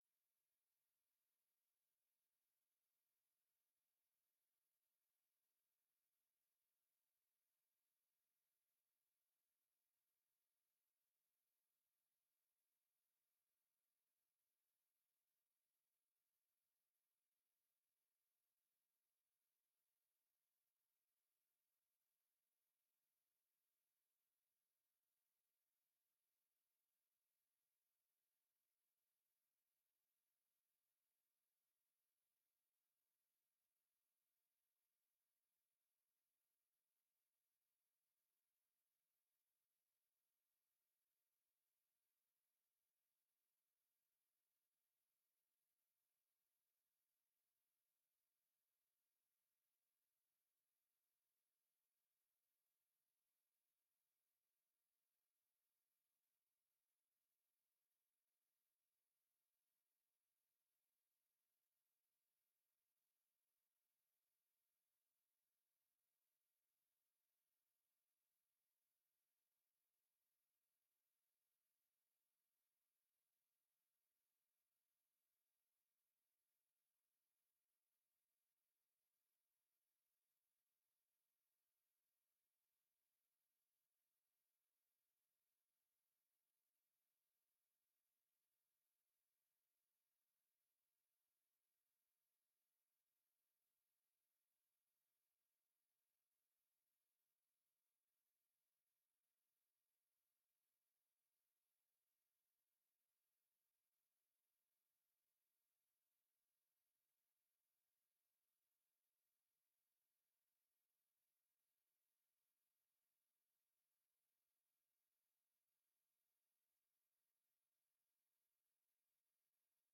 informatieve raadsbijeenkomst 04 juli 2024 19:30:00, Gemeente Doetinchem
Locatie: Raadzaal